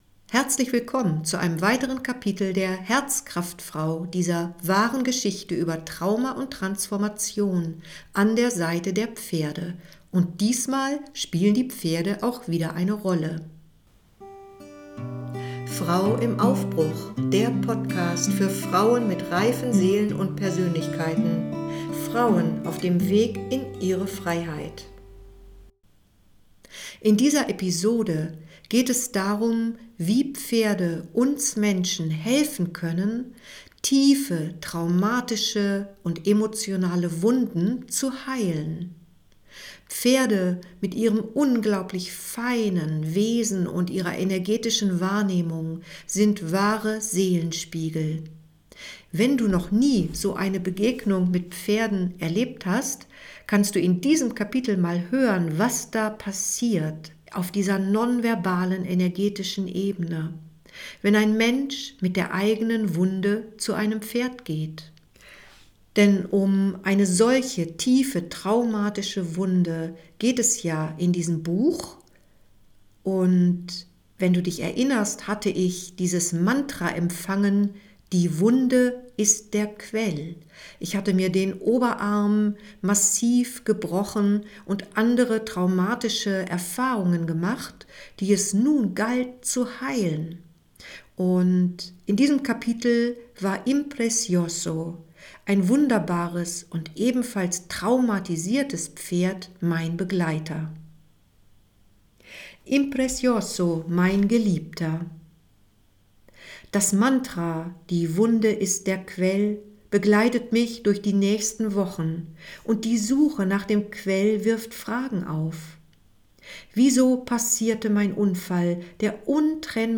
Beschreibung vor 3 Jahren #017 - HERZKRAFTFRAU 10 In dieser Episode lese ich vor und kommentiere, wie ich eine tiefgehende Begegnung mit dem Wallach Impressioso erlebte.